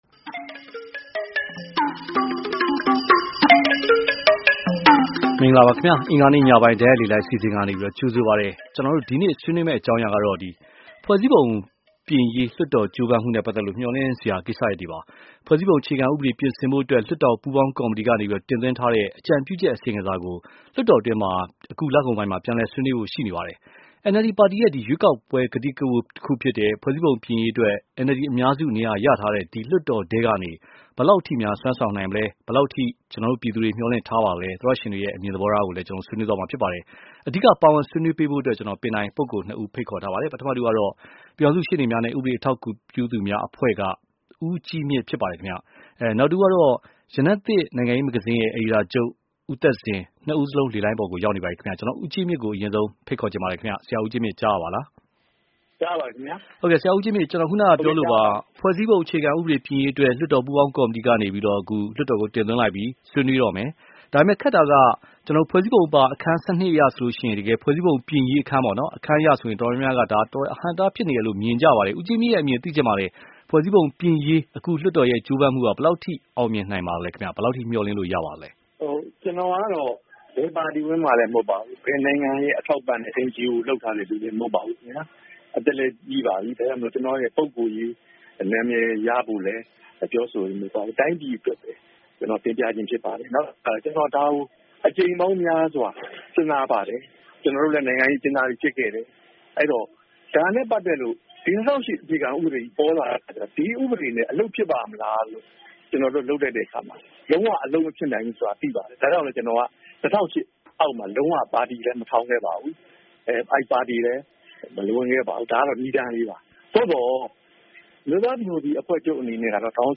ဖွဲ့စည်းပုံပြင်ရေးလွှတ်တော်ကြိုးပမ်းမှု မျှော်လင့်စရာ (တိုက်ရိုက်လေလှိုင်း)